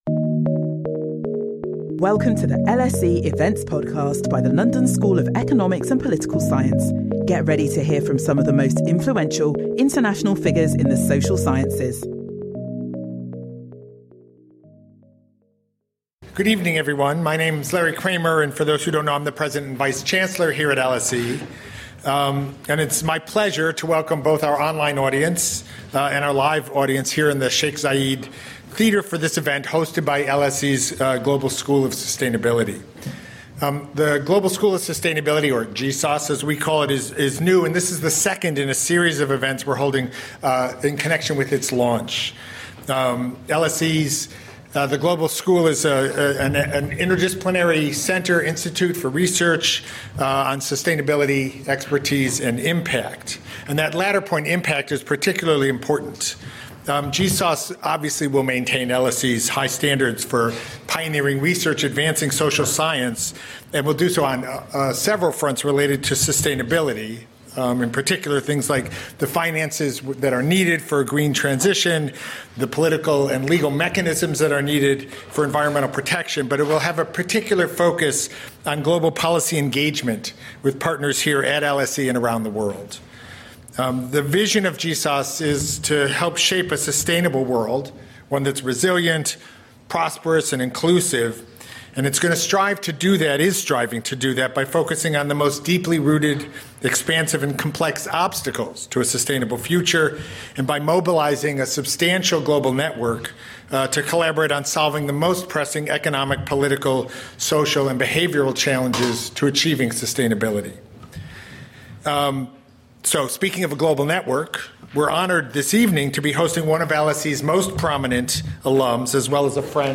Join Nobel Peace Prize Laureate and LSE alumnus Juan Manuel Santos and LSE academics Mary Kaldor and Nicholas Stern in a conversation to explore how we can build a sustainable, peaceful and stable world.